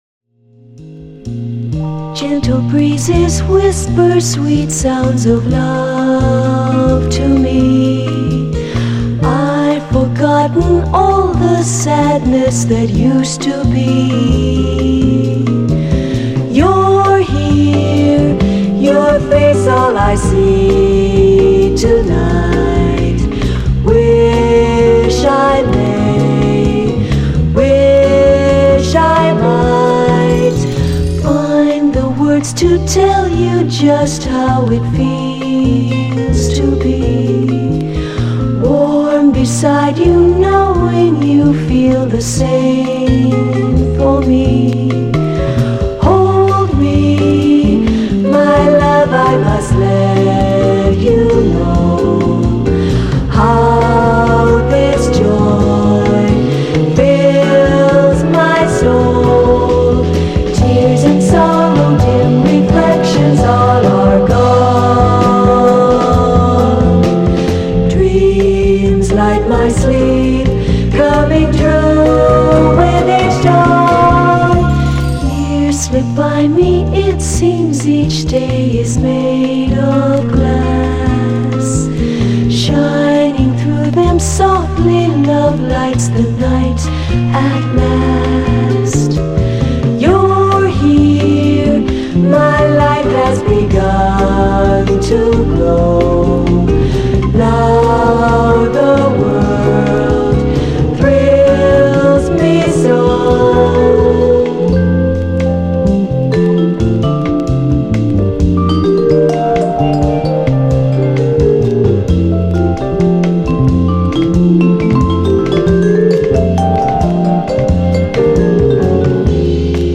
ジャジー＆ラテン・ソフトロック好盤！